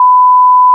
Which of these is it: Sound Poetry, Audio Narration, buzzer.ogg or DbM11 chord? buzzer.ogg